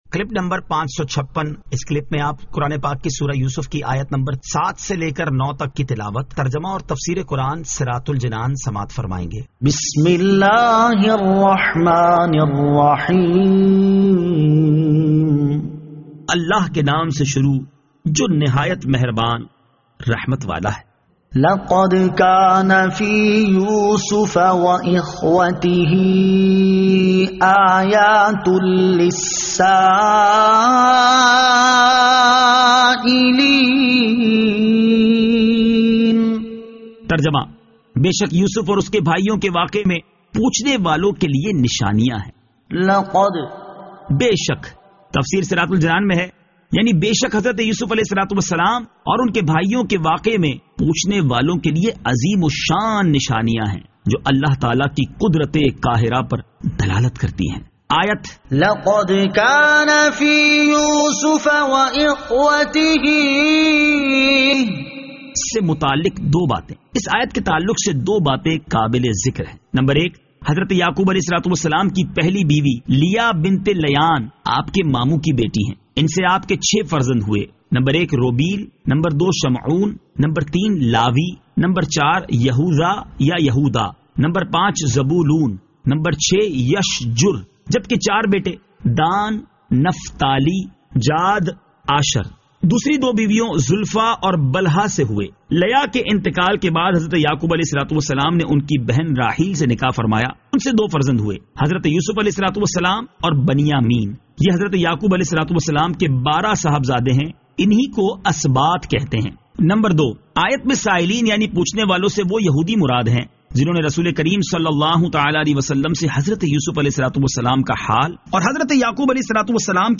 Surah Yusuf Ayat 07 To 09 Tilawat , Tarjama , Tafseer